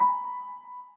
piano63.ogg